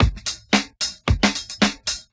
• 112 Bpm 1990s Funk Drum Groove A Key.wav
Free drum loop sample - kick tuned to the A note. Loudest frequency: 4700Hz